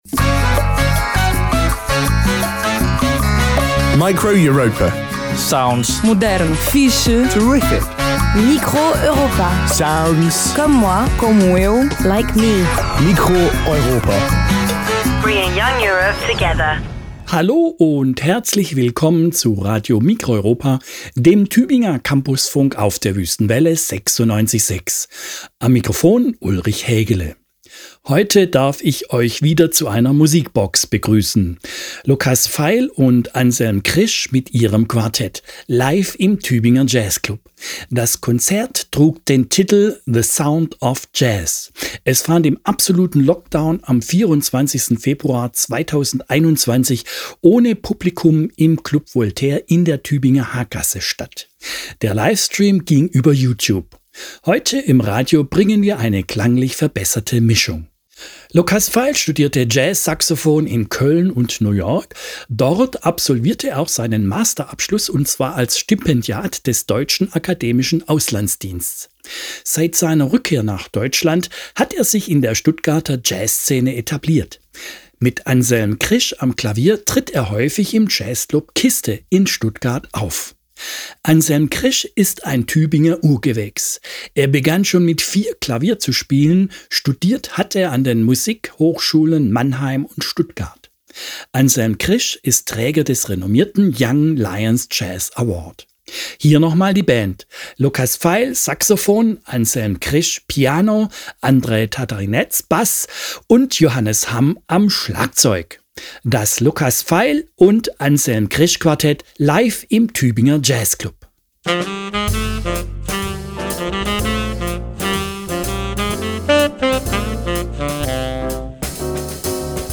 Geschmackvolle Arrangements von Stücken aus dem Great American Songbook sowie swingender, groovender Mainstream-Jazz.
sax
piano
bass
drums
Live-Aufzeichnung, geschnitten